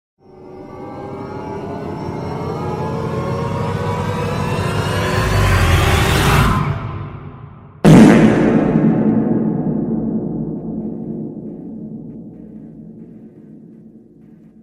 Loud Thunder Sound Effect Free Download
Loud Thunder